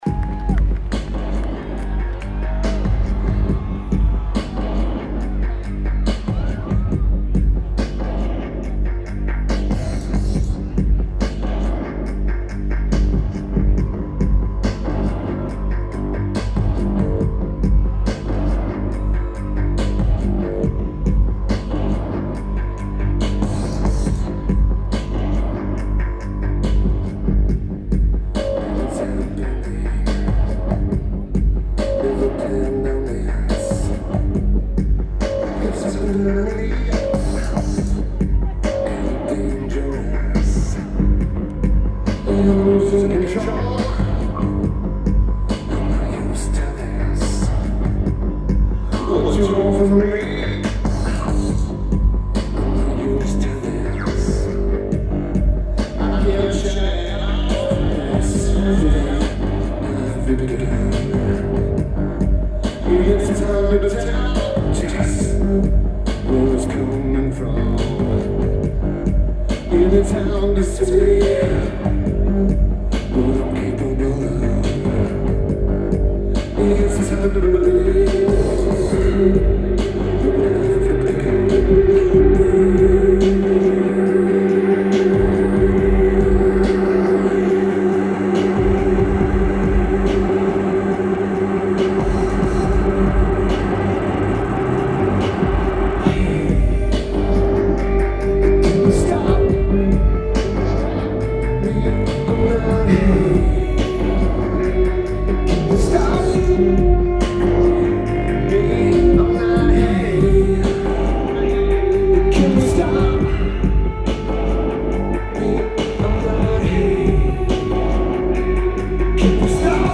Zappos Theater at Planet Hollywood
Lineage: Audio - AUD (AT853 (4.7k mod) + Sony PCM-A10)